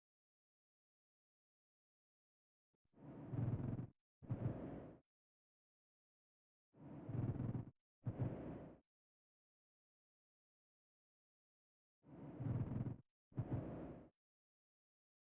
Auscultación
Frote_pleural.mp3